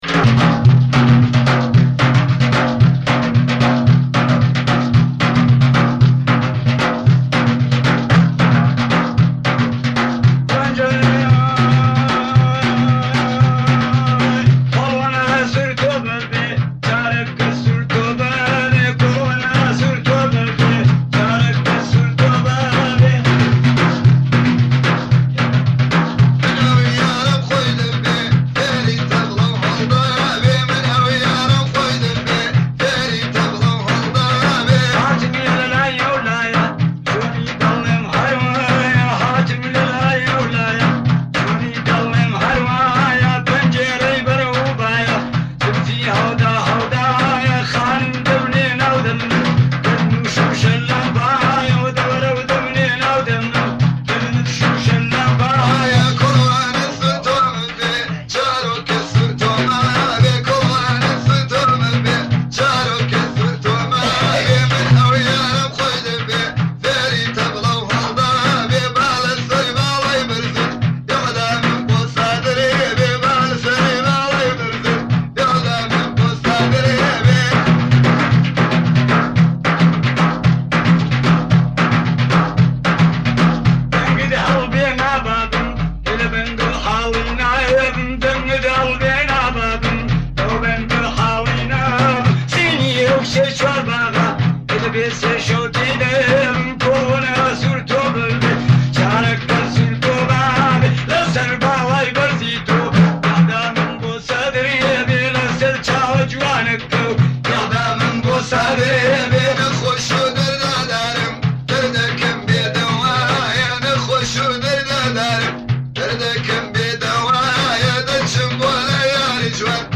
جدیدترین اهنگ های کردی شاد